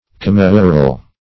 Meaning of commissural. commissural synonyms, pronunciation, spelling and more from Free Dictionary.
Commissural \Com*mis"su*ral\ (? or ?), a.